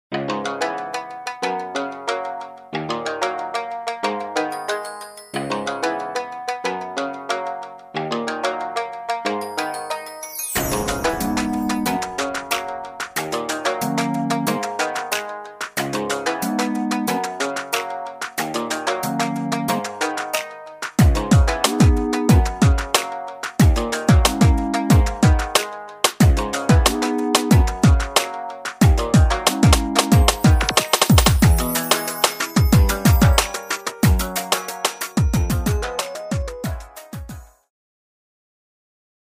Backing Track without Vocals for your optimal performance.